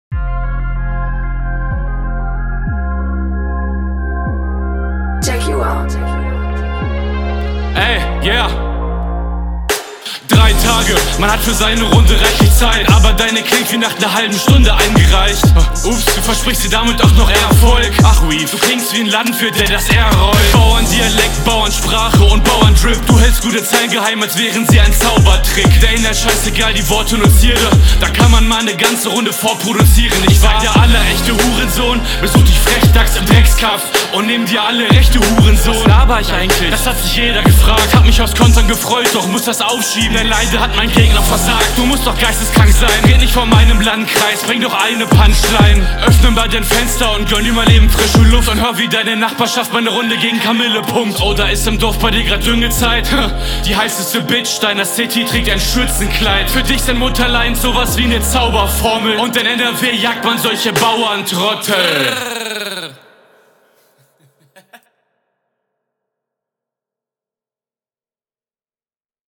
Flowlich schlechter, aber inhaltlich besser, auch wenn ich die ganzen Bauern Lines nicht so fühle, …
Von der stimme her kommst du ähnlich auf den beat wie dein gegner, dein flow …